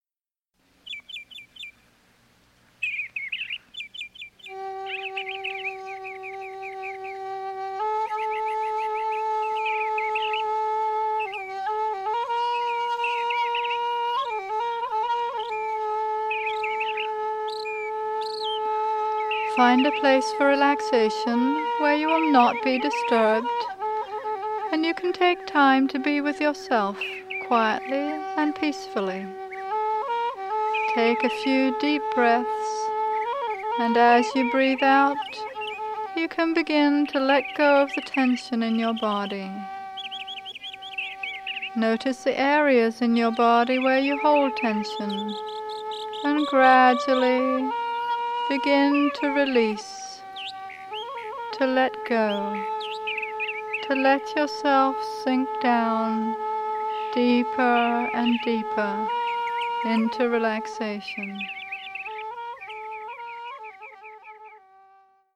Seasons for Healing: Spring (Guided Meditation)
Piano and Synthesizer
Flute